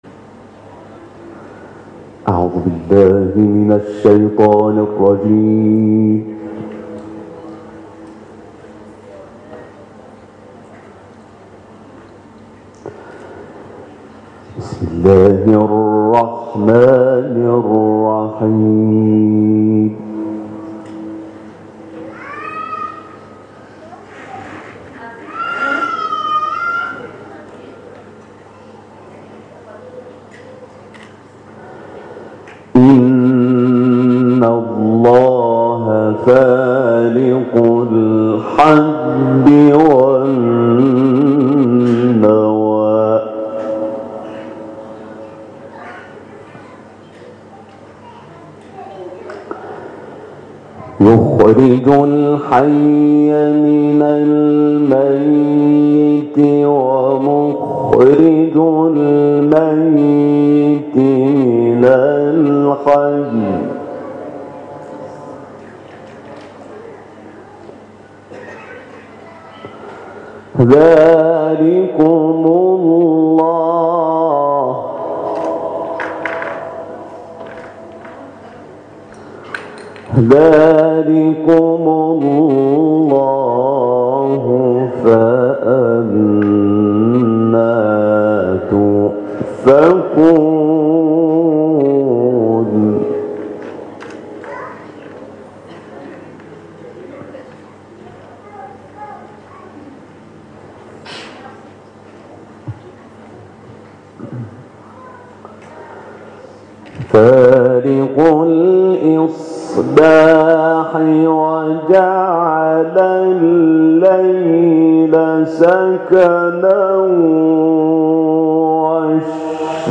تلاوت‌ سوره‌های «انعام» و «بلد» با صدای حمید شاکرنژاد
گروه فعالیت‌های قرآنی - صوت تلاوت آیاتی از سوره‌های «انعام» و «بلد» با صدای حمید شاکرنژاد، قاری بین‌المللی قرآن در این قسمت قابل پخش است.
تلاوت